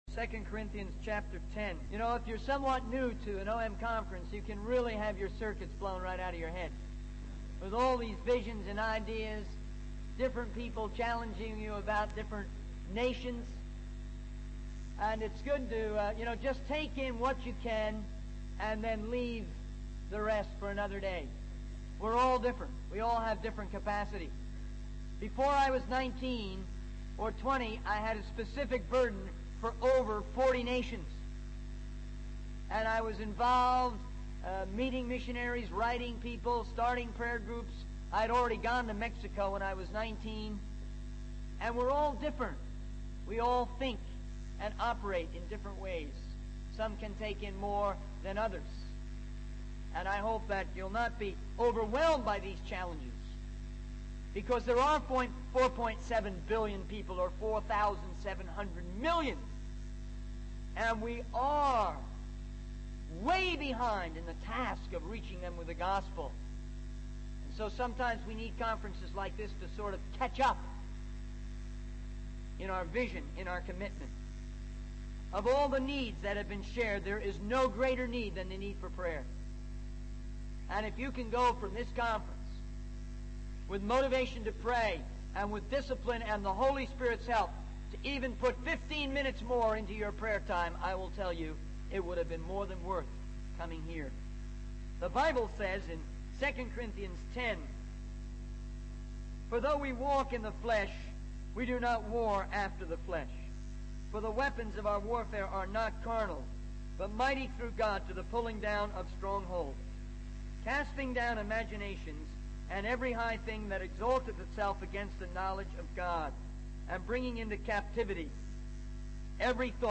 In this sermon, the speaker emphasizes the importance of reaching the billions of people in the world with the gospel of Jesus Christ. He highlights the need for prayer and encourages the audience to prioritize their prayer time, even adding an additional 15 minutes. The speaker also addresses the issue of misplaced priorities, urging people to redeem their time and focus on what truly matters.